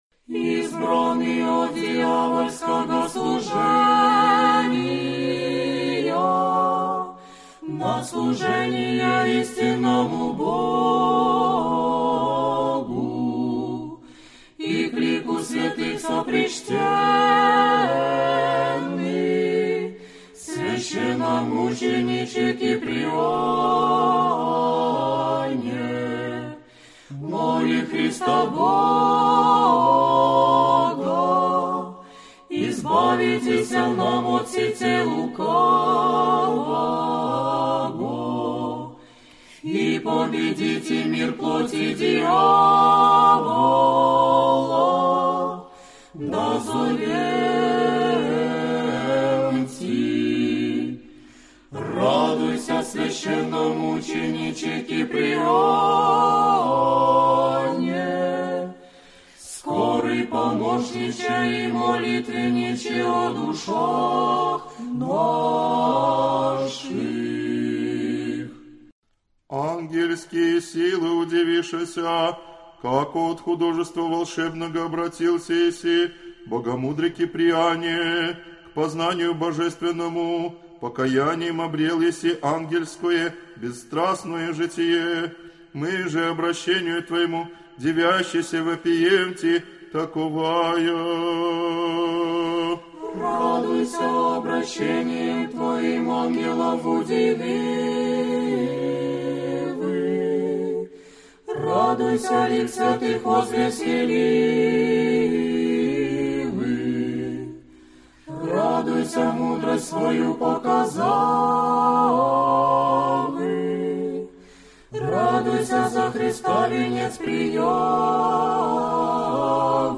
Aудиокнига Акафисты мр3 сборник Автор Сборник Читает аудиокнигу Хор села Дивеево.